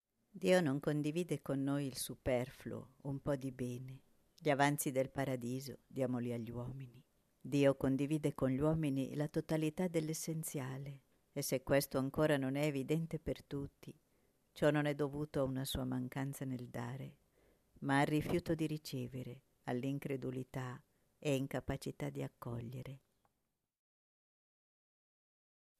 Preghiera mp3